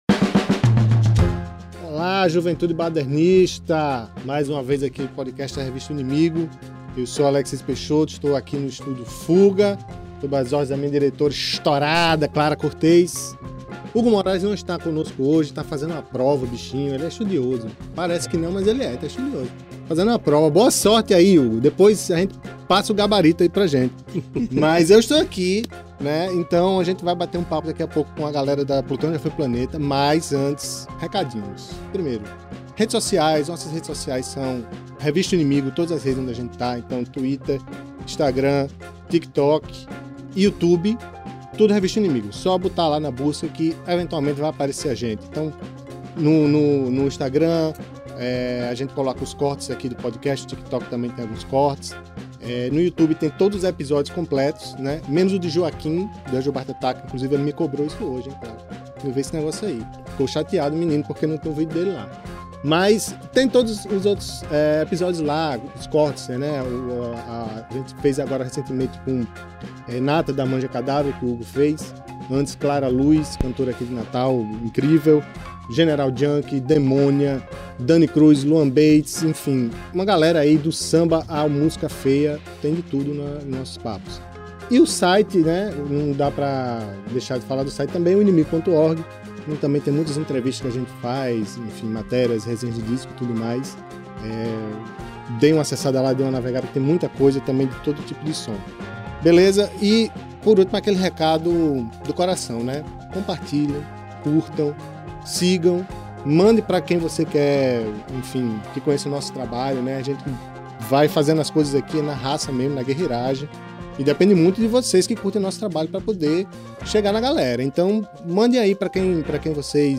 Gravado e editado no Estúdio Fuga, Natal/RN.
revista-o-inimigo-entrevista-plutao-ja-foi-planeta.mp3